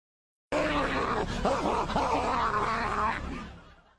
Play Barking Man - SoundBoardGuy
Play, download and share Barking Man original sound button!!!!
barking-man.mp3